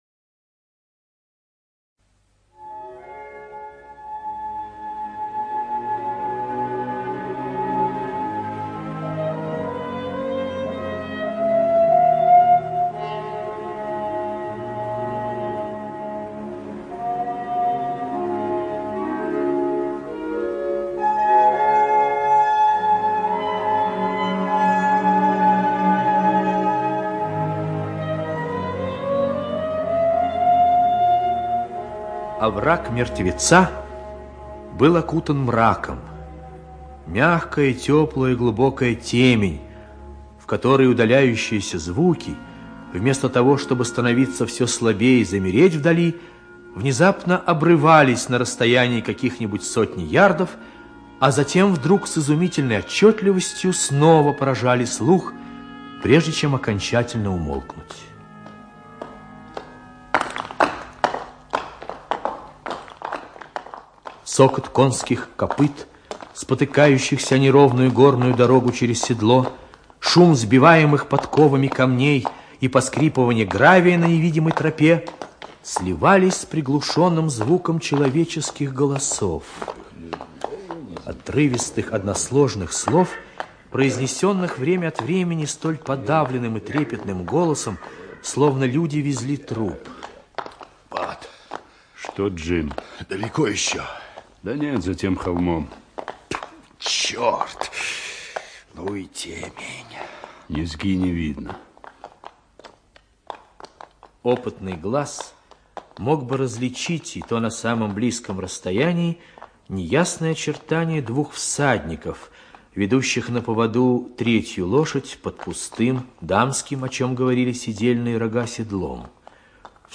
ЖанрРадиоспектакли
Радиопостановка по рассказу австралийского писателя Генри Лоусон "Новогодняя ночь".